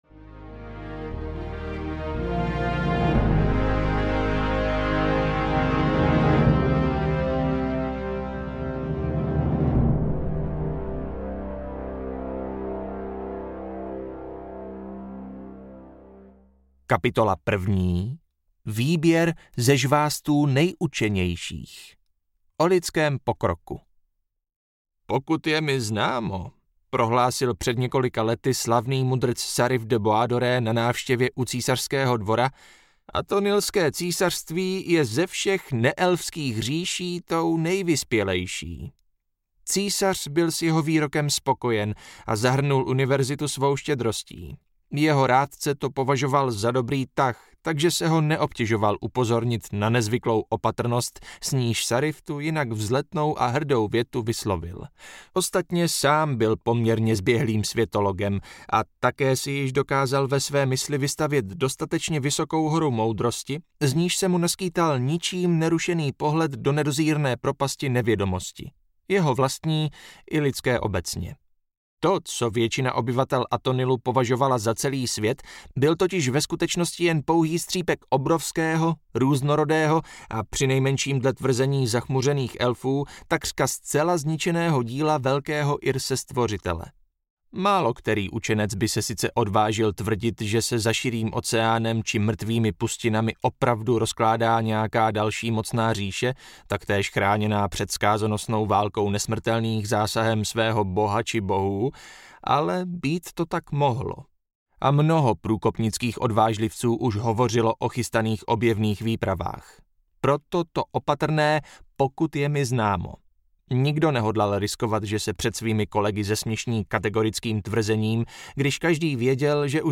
Stříbrná díra audiokniha
Ukázka z knihy